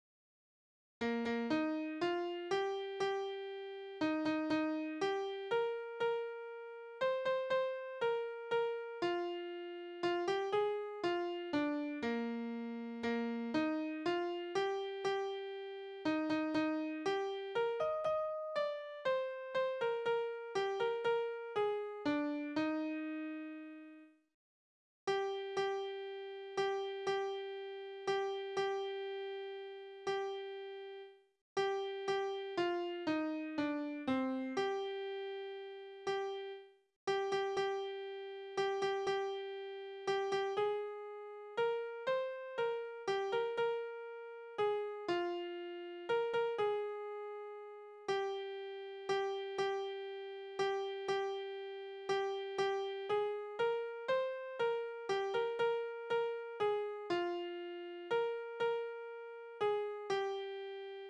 Berufslieder: Fischerleben
Tonart: Es-Dur
Taktart: 3/4
Tonumfang: Undezime
Besetzung: vokal